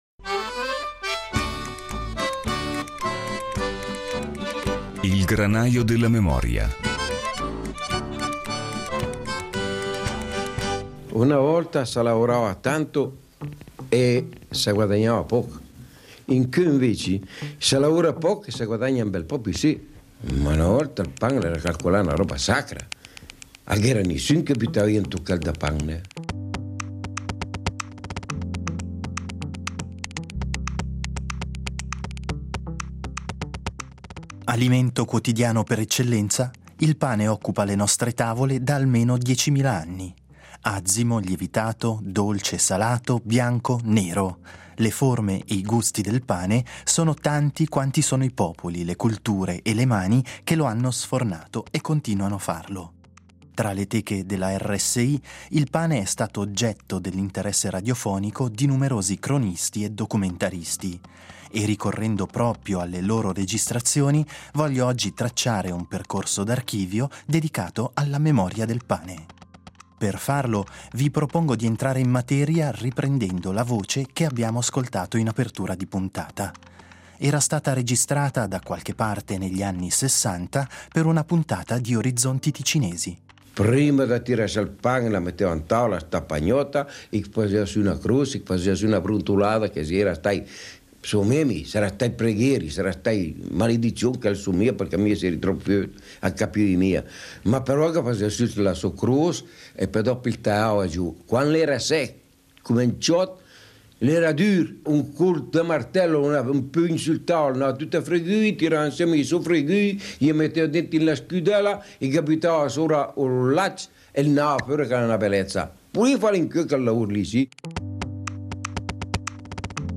Tra le teche della RSI il pane è stato oggetto dell’interesse radiofonico di numerosi cronisti e documentaristi. Ricorrendo alle loro registrazioni il “Granaio della memoria” traccerà questa settimana un percorso d’archivio dedicato alla memoria del pane.